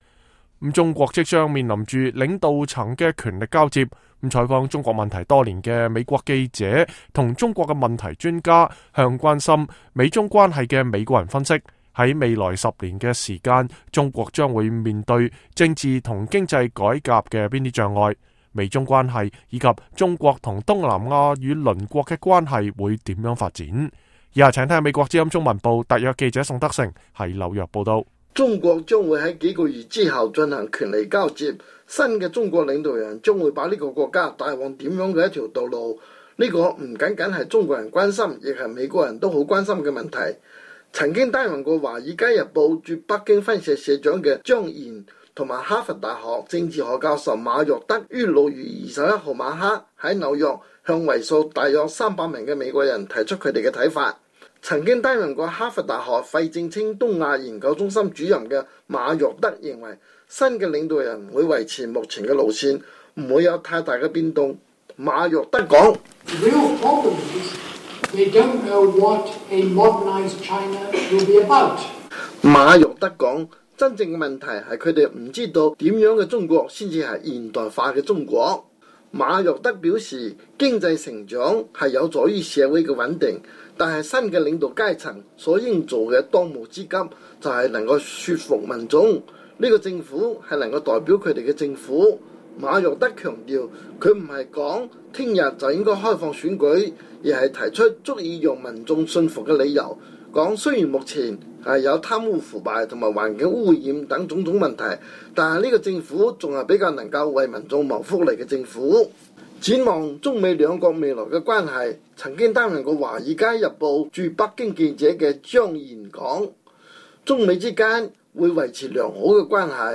哈佛大學政治學教授馬若德, 前華爾街日報駐北京記者張彥, 亞洲協會美中關係主任夏偉
中國將會在幾個月後進行權力交接，新的中國領導人將會把這個國家帶往甚麼樣的一條道路，這不僅是中國人關心，也是美國人很關心的問題。曾經擔任華爾街日報駐北京分社社長的張彥(Ian Johnson)，和哈佛大學政治學教授馬若德(Roderick MacFarquhar)，於6月21日晚在紐約向為數約三百名的美國人，提出他們的看法。